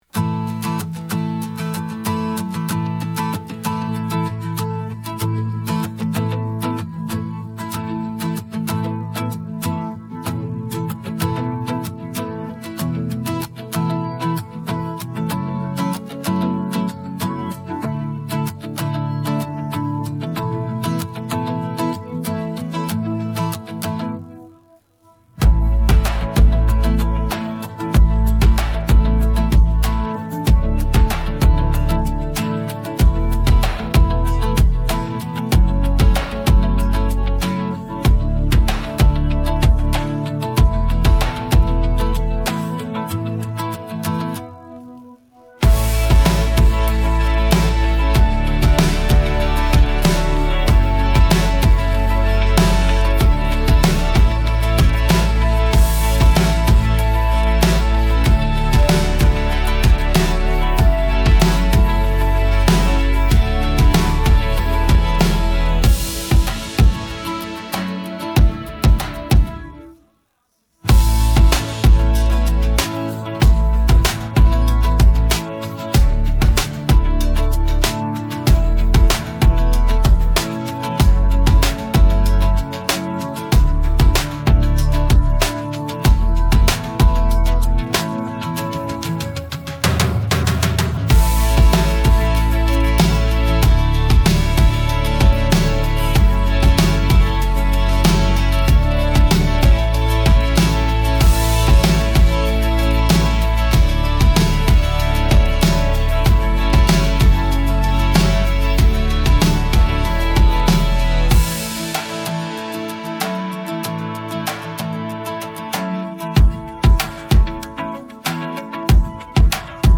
gotmres25-26_Together-Instrumental.mp3